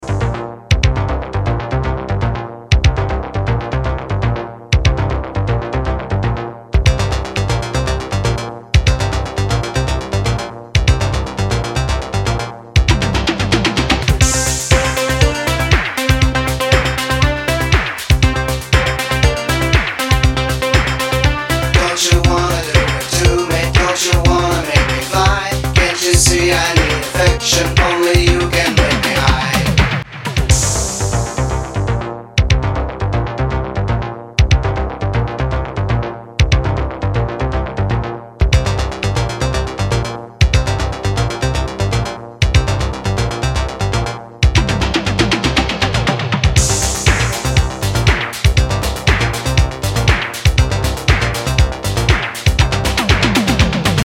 Synth Pop
электронная музыка
disco
80-е